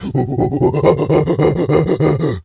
Animal_Impersonations
gorilla_chatter_09.wav